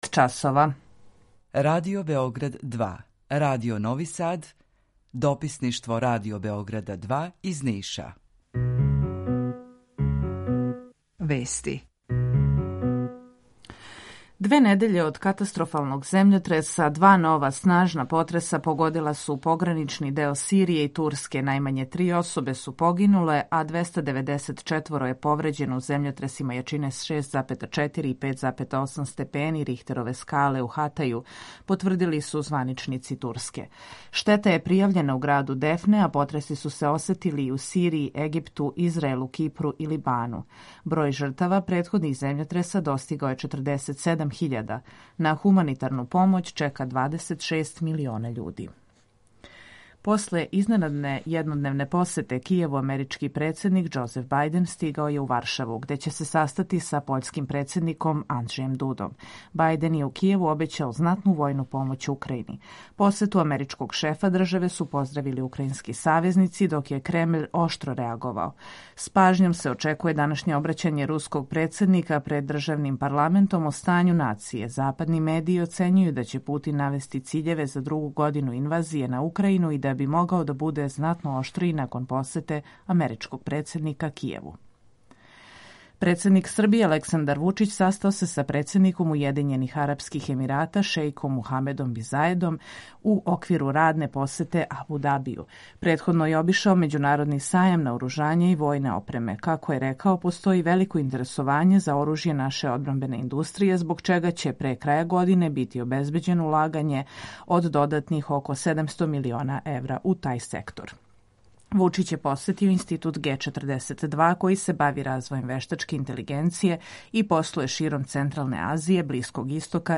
Емисију реализујемо уживо са Радиом Републике Српске у Бањалуци и Радио Новим Садом; Тема: Kо су возачи који нас возе у градском и међуградском јавном превозу
Јутарњи програм из три студија
У два сата, ту је и добра музика, другачија у односу на остале радио-станице.